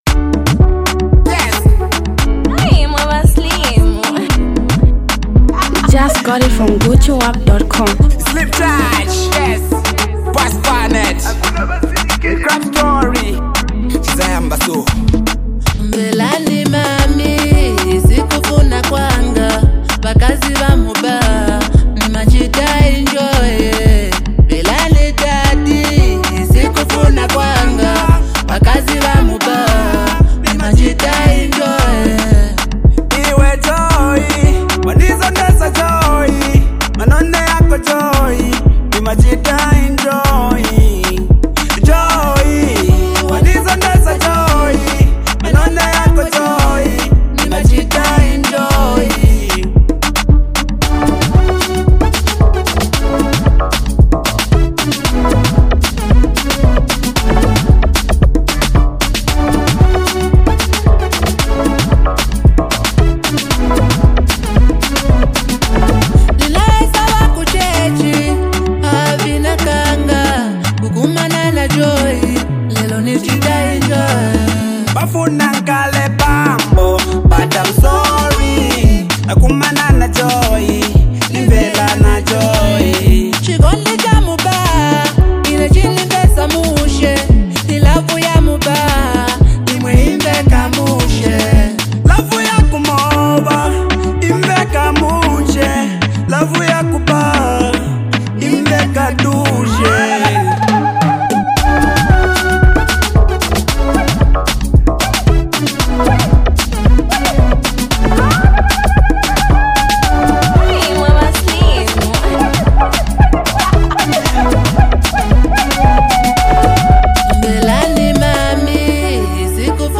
Zambian Mp3 Music
club hit song